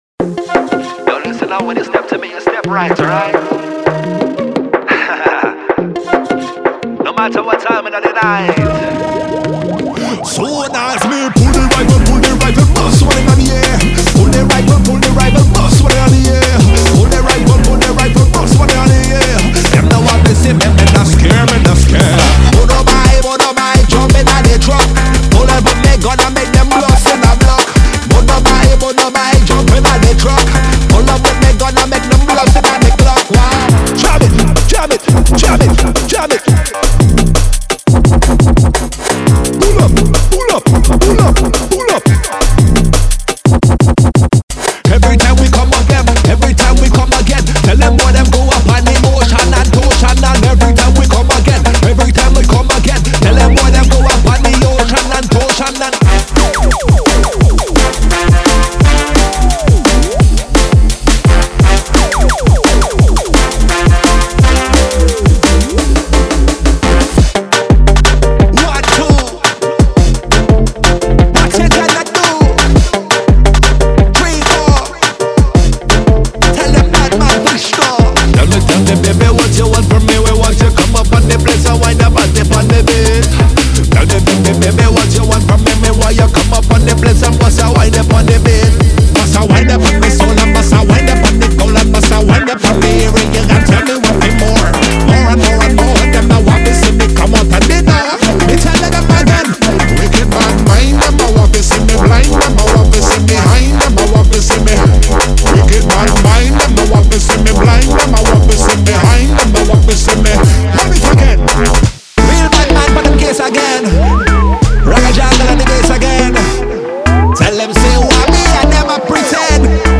Genre:Drum and Bass
デモサウンドはコチラ↓
172 BPM
110 Vocal Loops (55 Dry, 55 Wet)